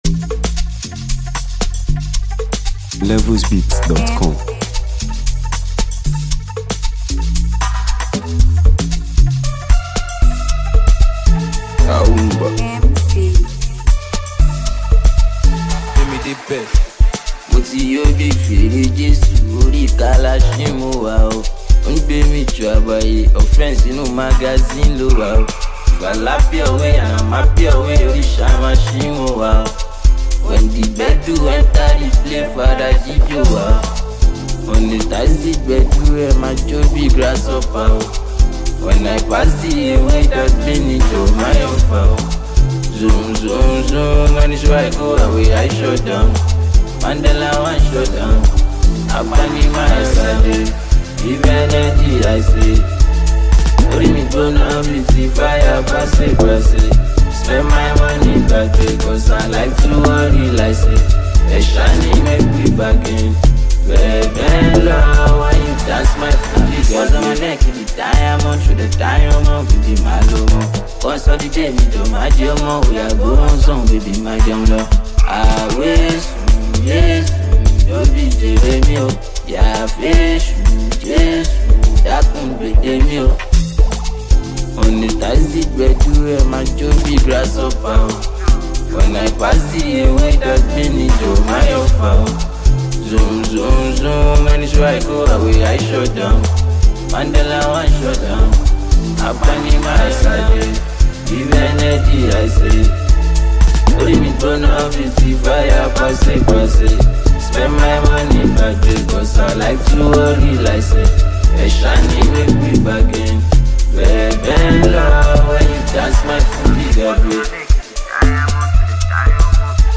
With its infectious rhythm and memorable melodies